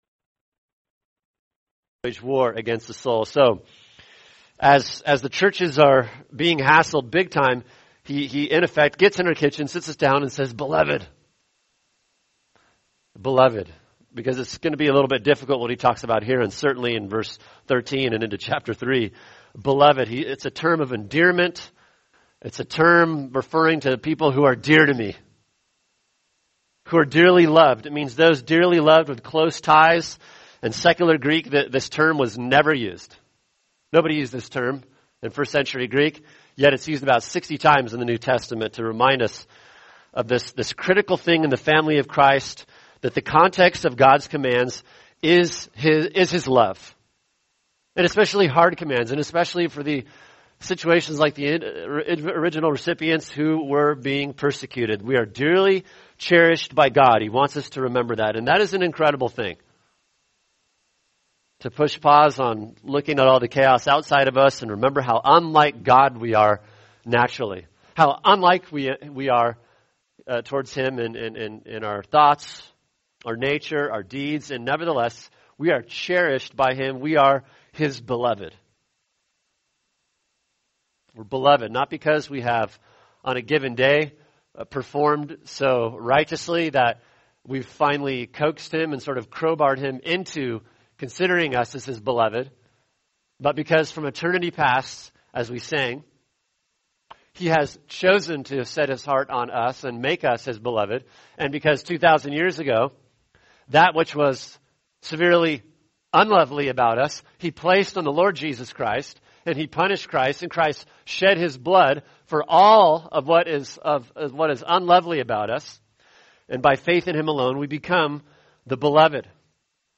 [sermon] 1 Peter 2:11-12 War & Witness | Cornerstone Church - Jackson Hole